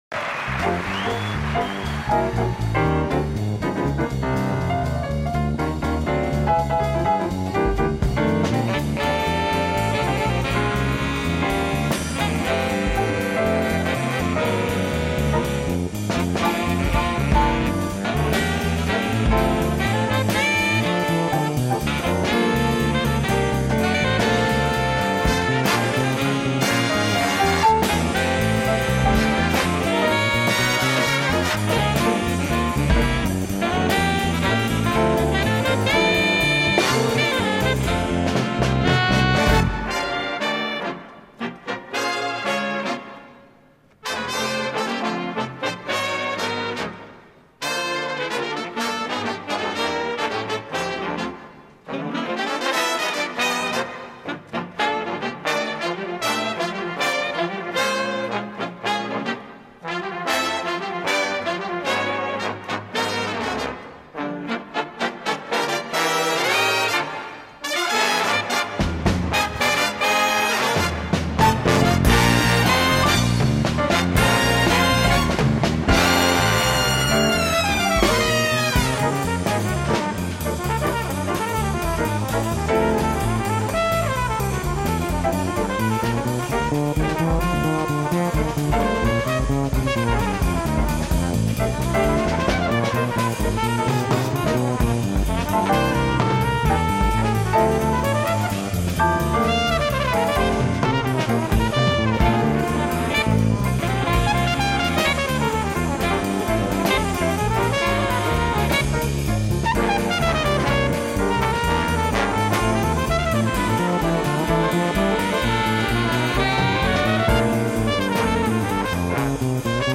Мое "детище" - биг бэнд УРВО. Запись с концерта, ух, бас там в линию...)